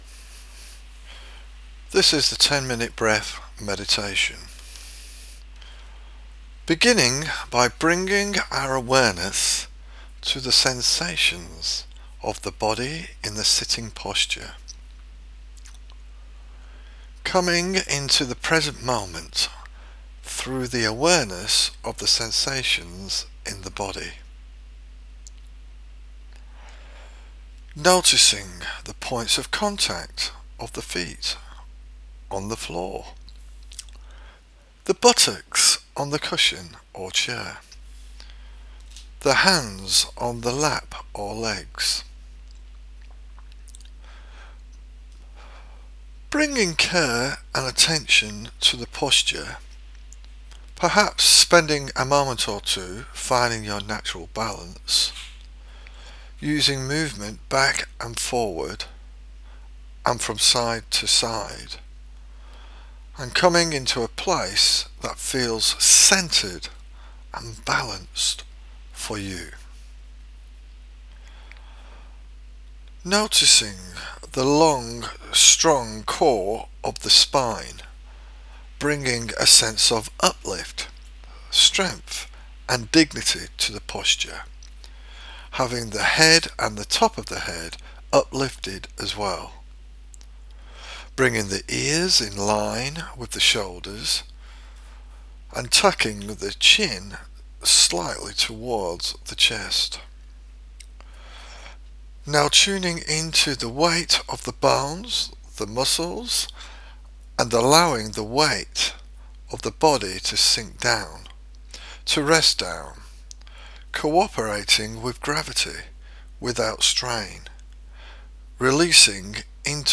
Meditation on the Breath
10-min-breath-meditation.m4a